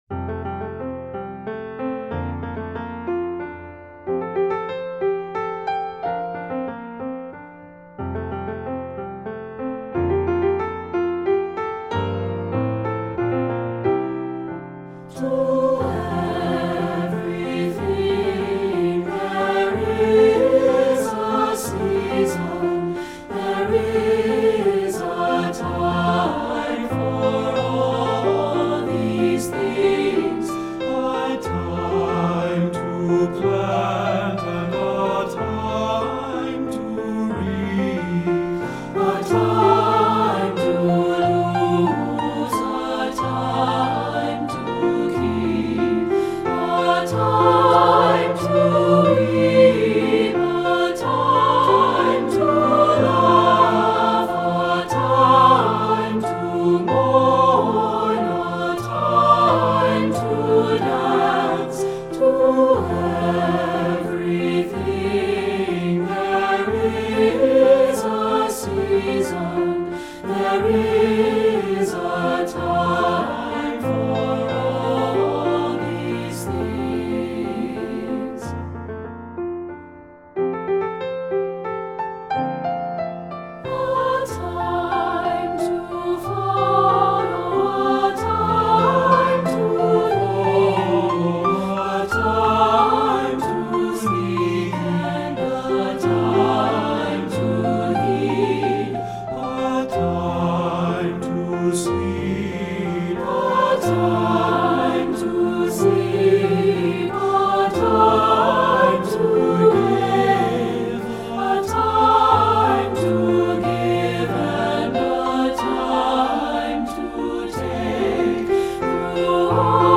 Voicing: 3-Part Mixed and Piano